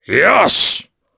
Kaulah-Vox_Happy4_jp.wav